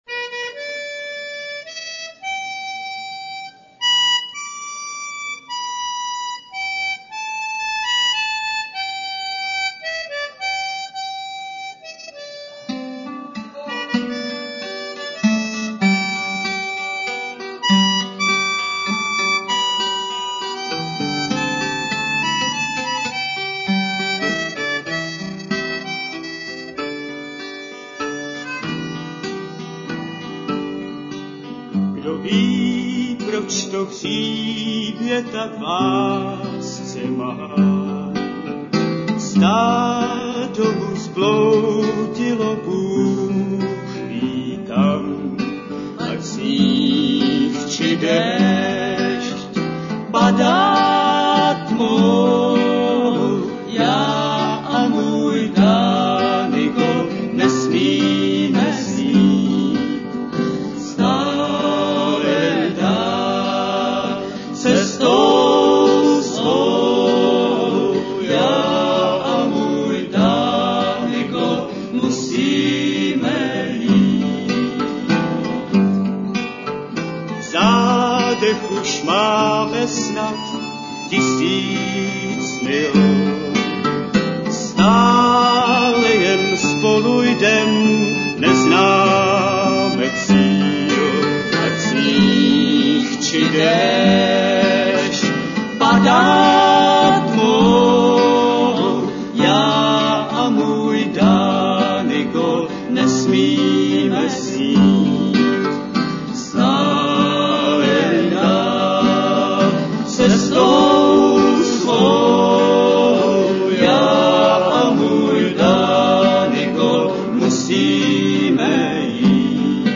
Nahrávka z prvního vystoupení na Portě z roku 1968